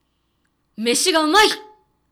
ボイス
中性